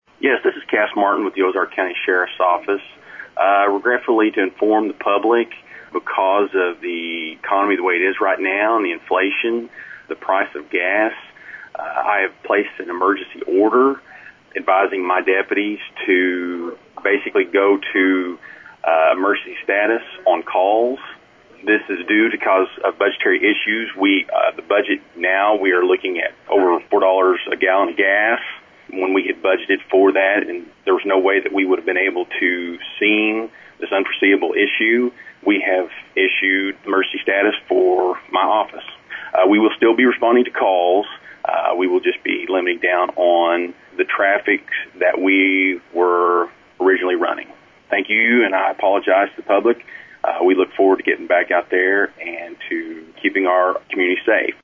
Ozark Radio News spoke with Sheriff Martin about the Order: